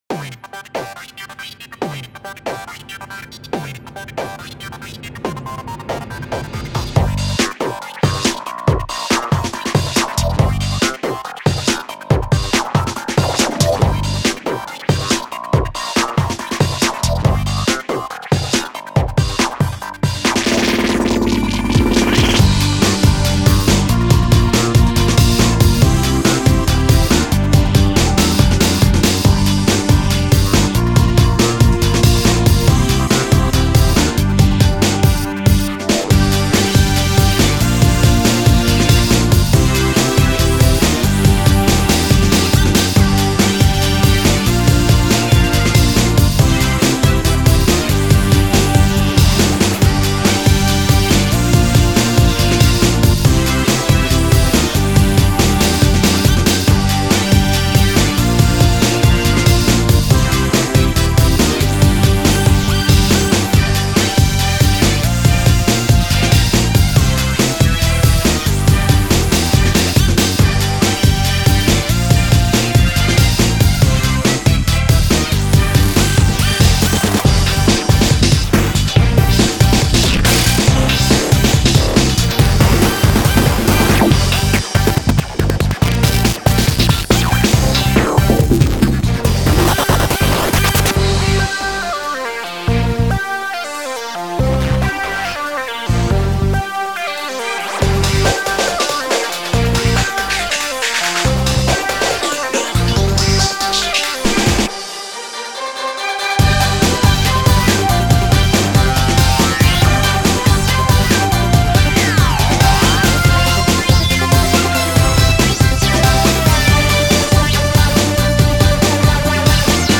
video game remix